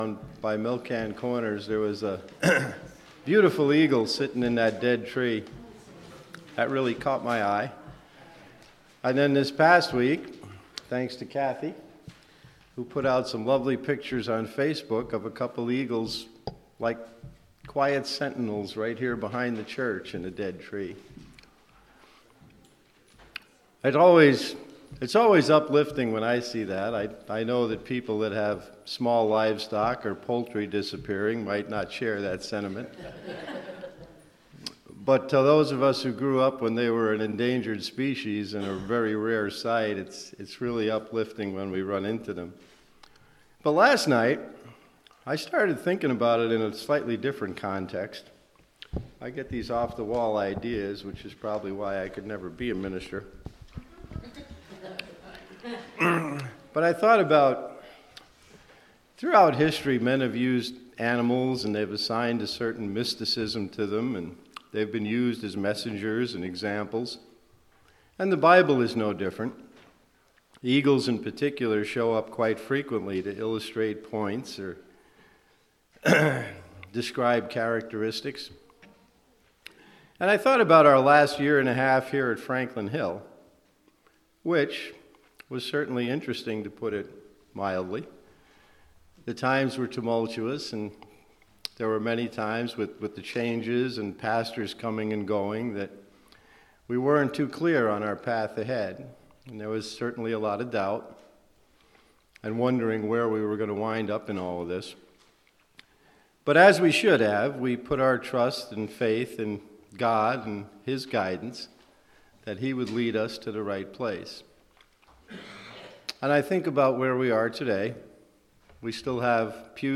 Worship Service – October 20, 2024 « Franklin Hill Presbyterian Church
Scripture Reading